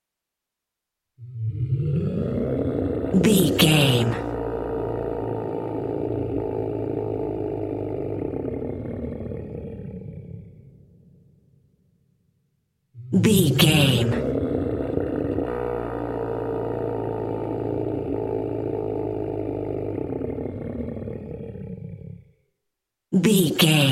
Dinosaur angry scream big creature with without rvrb
Sound Effects
ominous
dark
angry